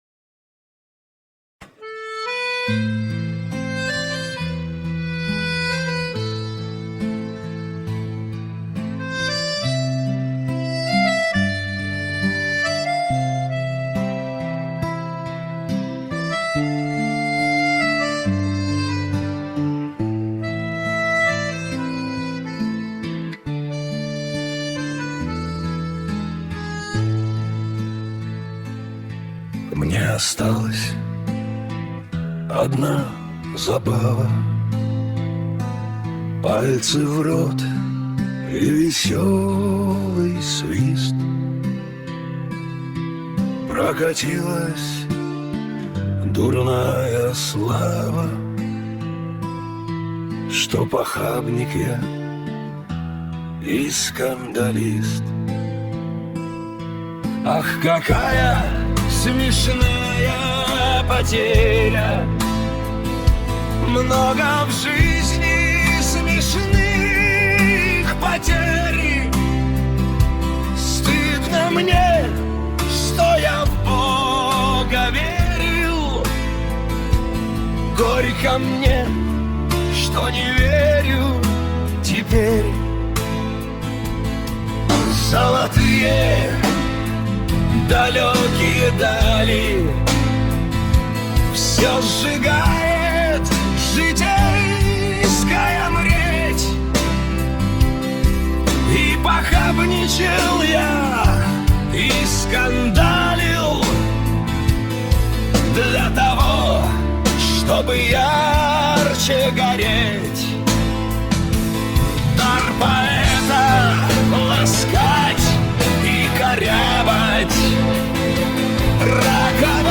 stereo Поп музыка